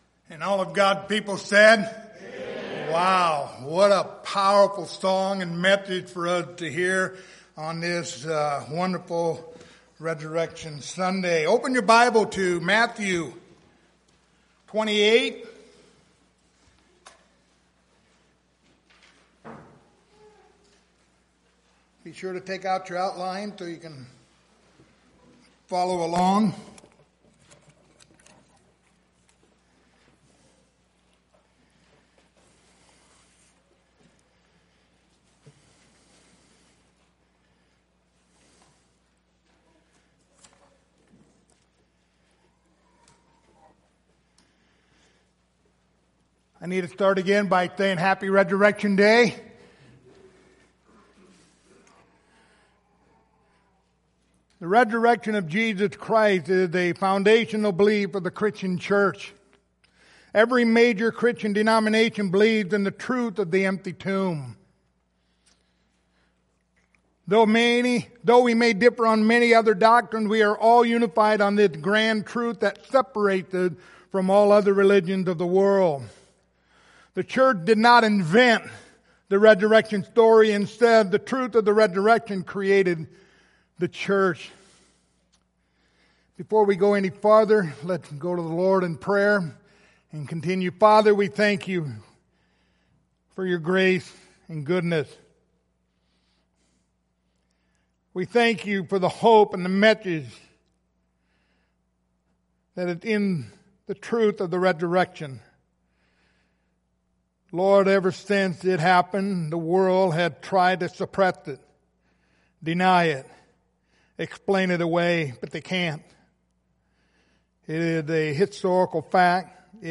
John 20:24-31 Service Type: Sunday Morning Download Files Notes Topics